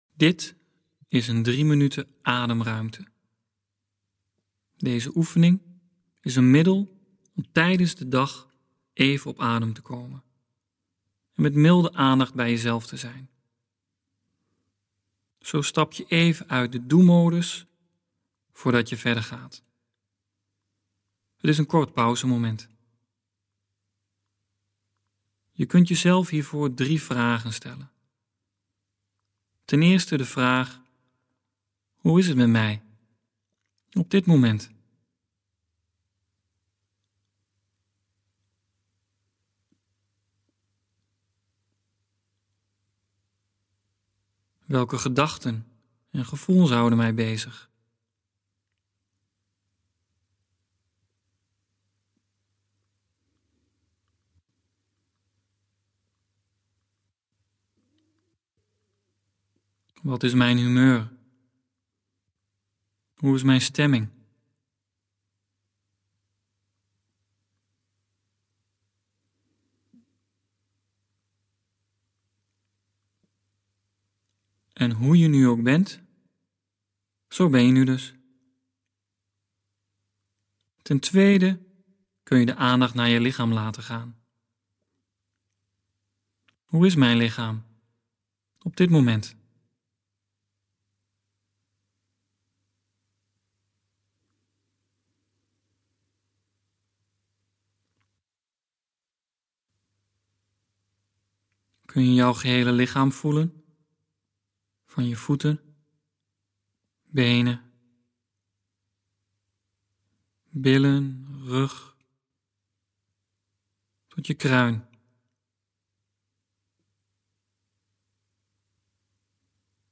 Oefening